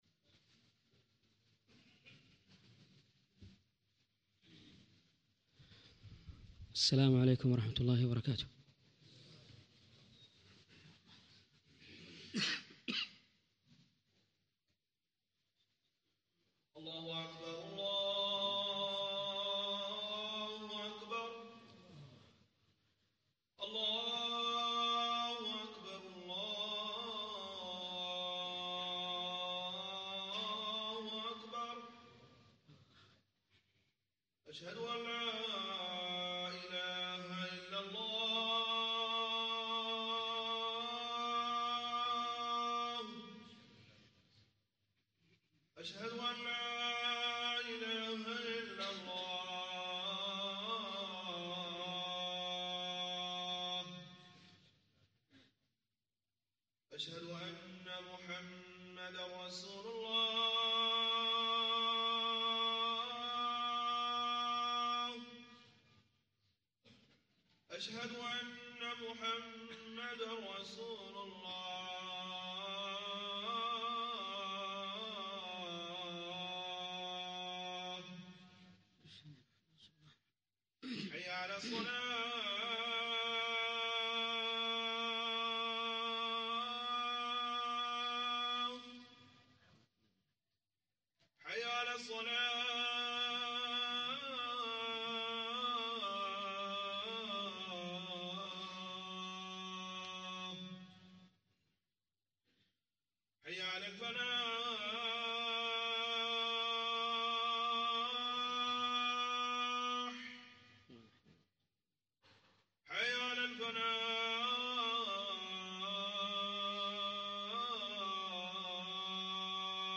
الخطبه